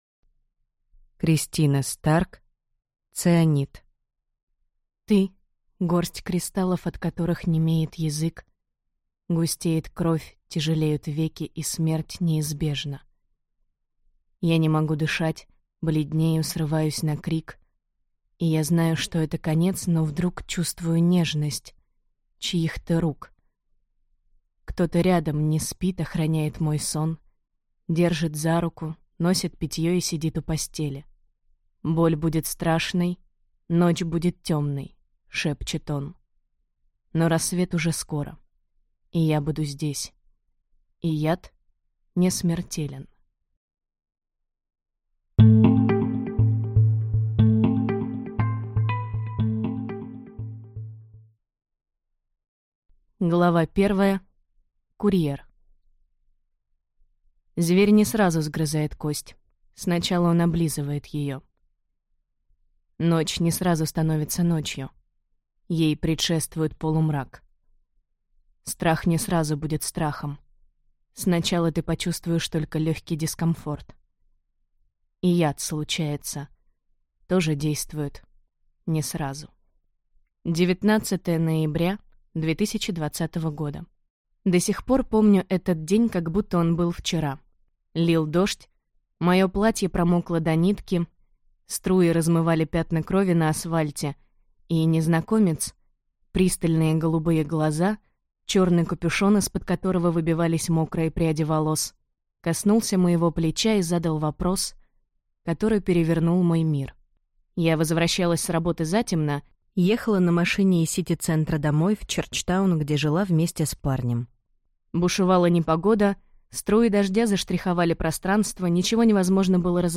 Аудиокнига Цианид | Библиотека аудиокниг